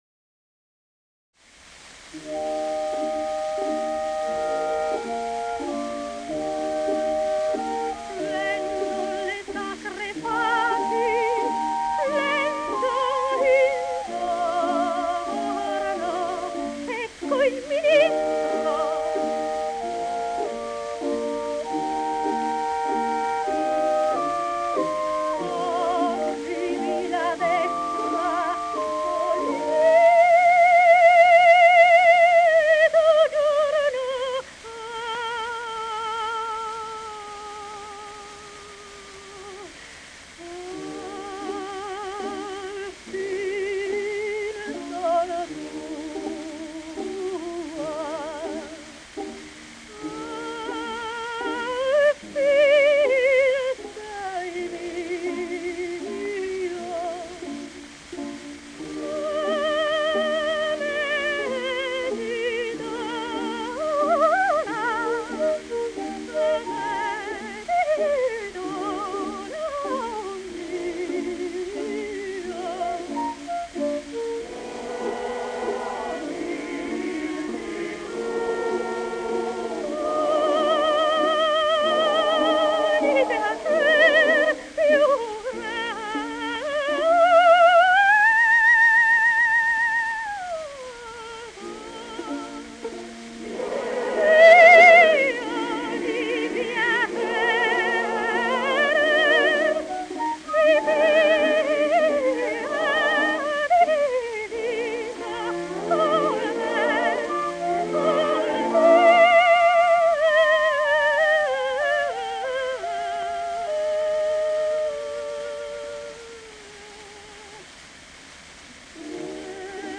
ДАЛЬ MОHTE (Dal Monte) Тоти (наст. имя и фамилия - Антониетта Mенегелли, Meneghelli) (27 VI 1893, Мольяно-Венето - 26 I 1975) - итал. певица (колоратурное сопрано).
Редкий по красоте тембра, гибкости и яркости звучания голос, виртуозное вок. мастерство, музыкальность и артистич. дарование поставили Д. М. в ряд выдающихся оперных певиц мира.